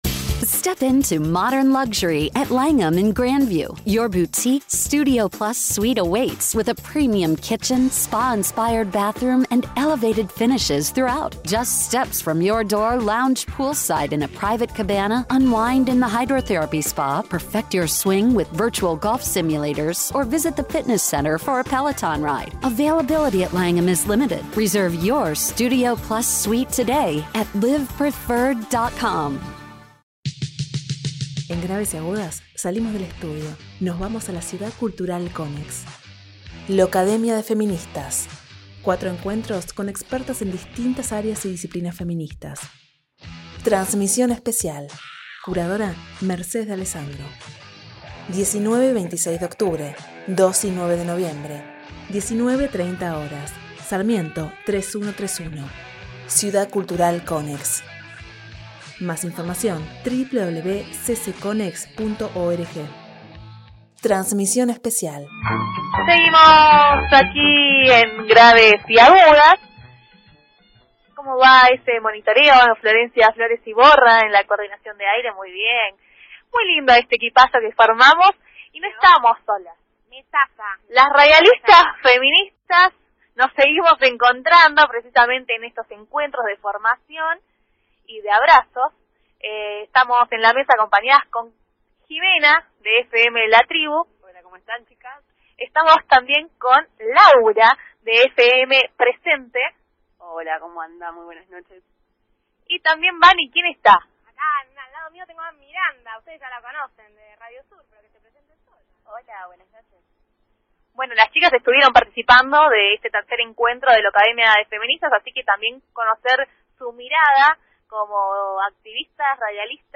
Una mesa improvisada pero contundente.